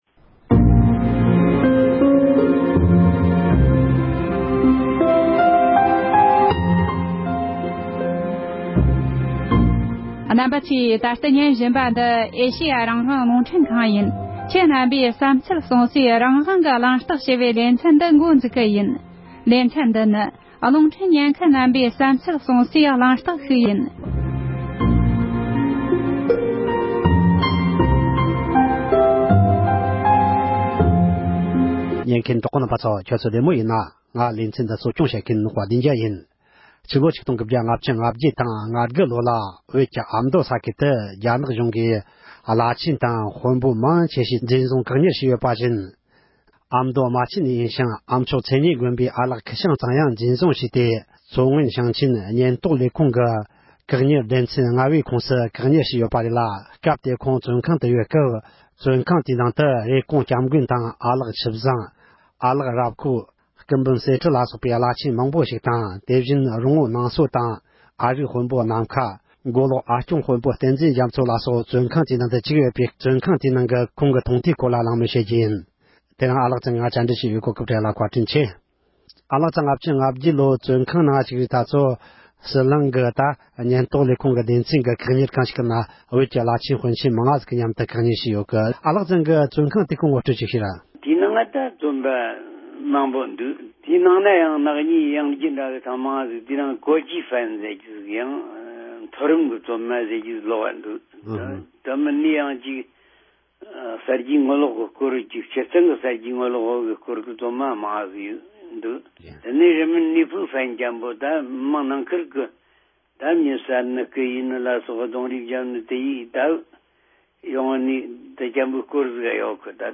ཁོང་དང་ལྷན་དུ་གླེང་མོལ་ཞུས་པར་གསན་རོགས་གནོངས༎